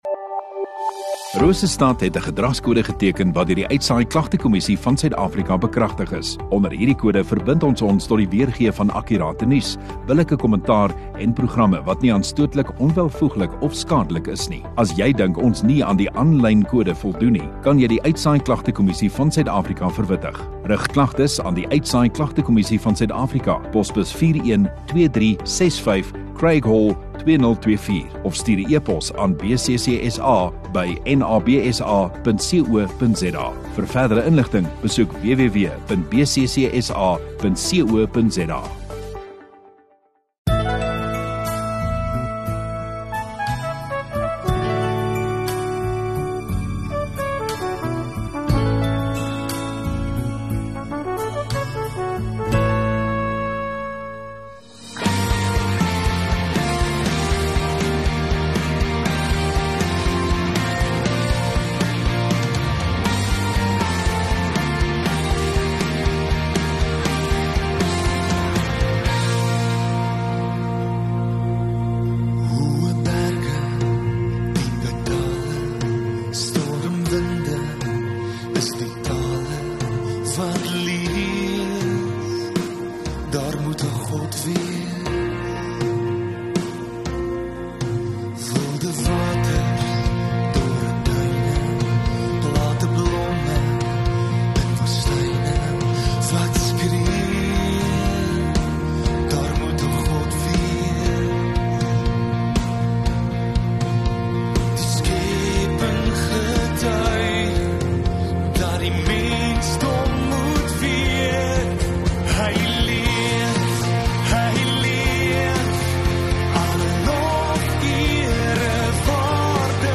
10 Aug Sondagaand Erediens